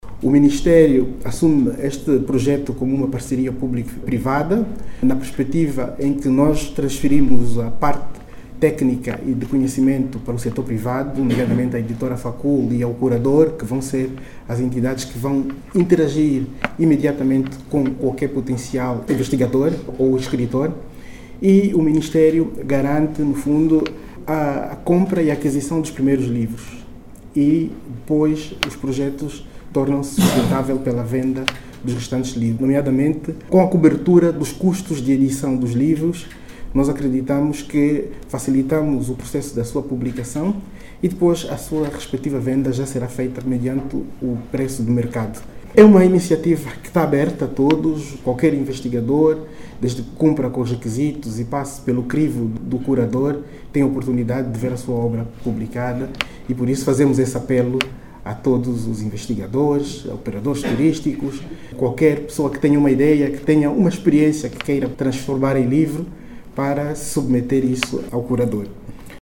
O secretário de Estado para o Turismo, Augusto Kalikemana, destacou a importância destes dois projectos.